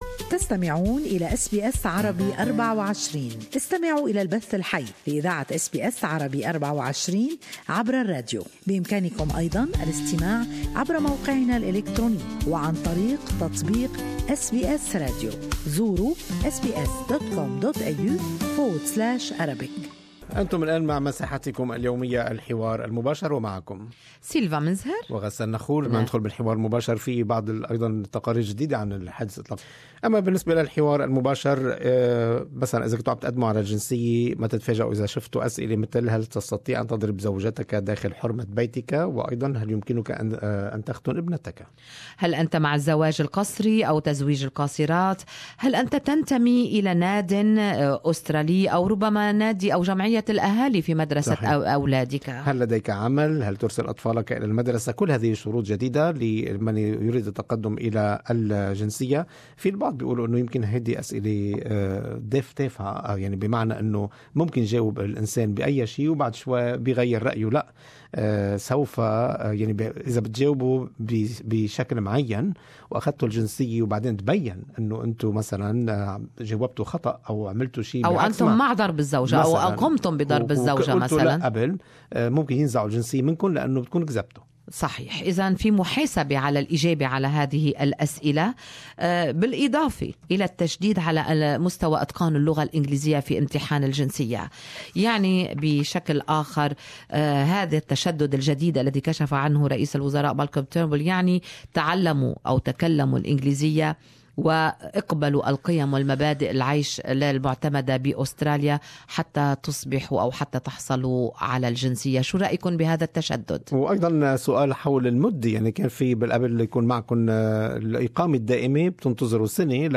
To become Australian citizens applicants will need to have been a permanent resident for four years - up from 12 months now - face a stand-alone English test and commit to embracing Australian values; Good Morning Australia 6-8 AM program on SBS Arabic 24 opened its line for listeners comments in a live talk back segment.